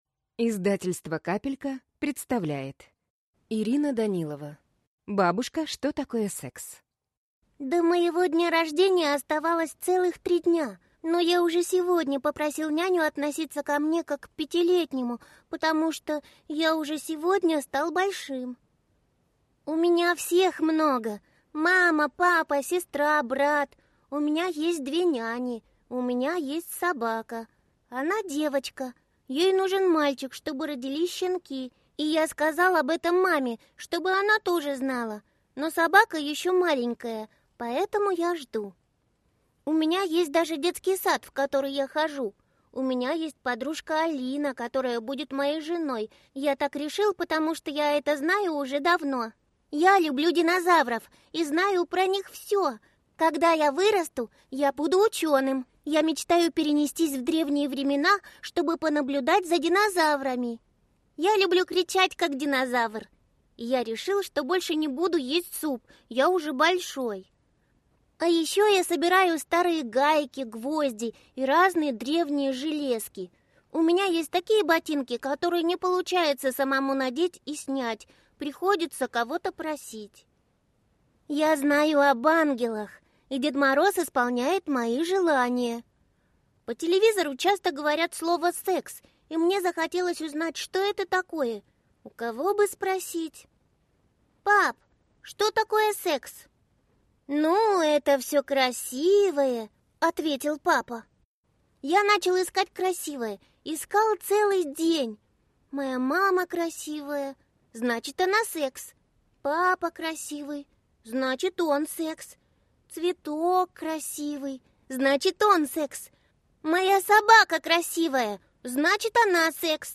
Аудиокнига Бабушка, что такое С…?